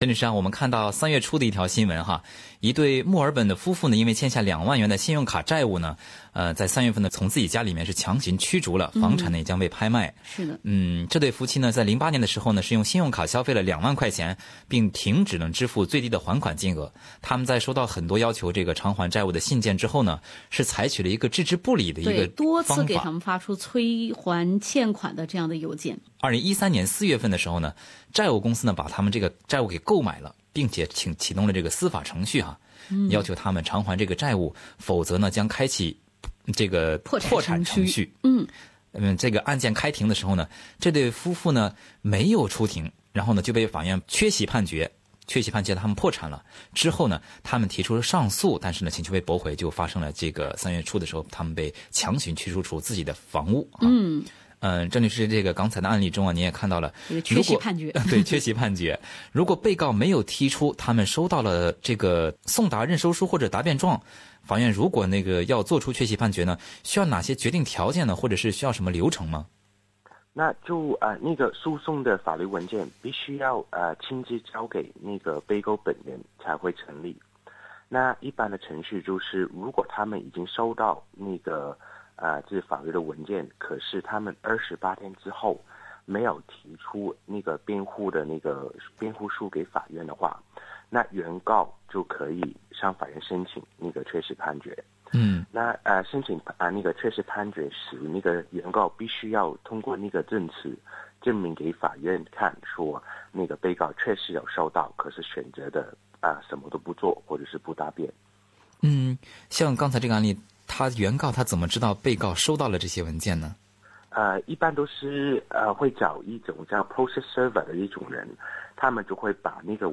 本期节目也有听众咨询婚姻财产方面相关的法律问题，可供您参考。